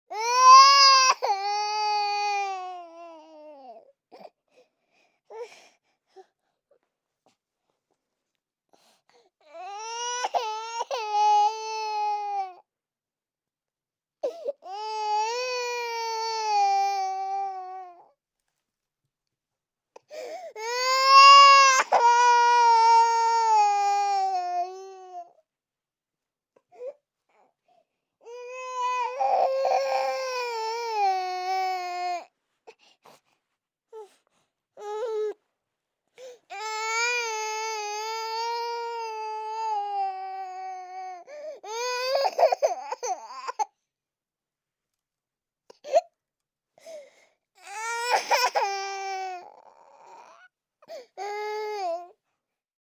Baby-F_Crying_VKT_17361.wav